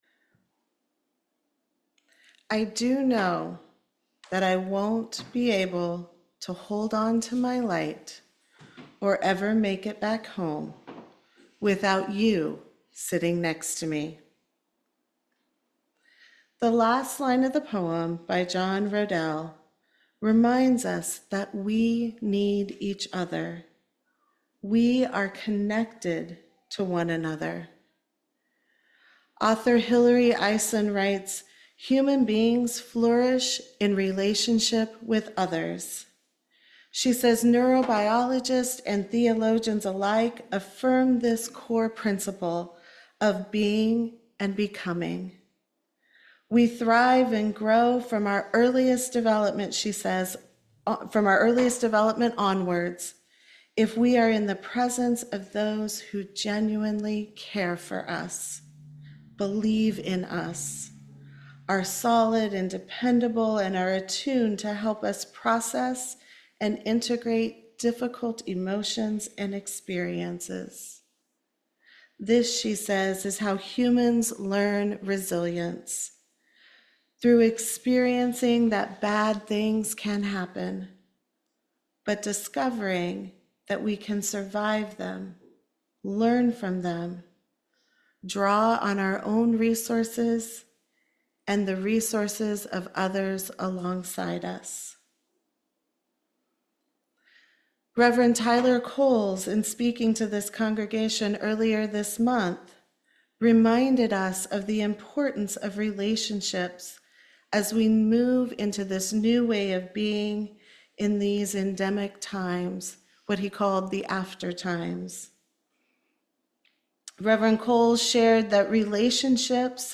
This sermon emphasizes that human flourishing is fundamentally rooted in deep, authentic relationships rather than casual social interactions.